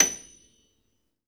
53d-pno28-F6.aif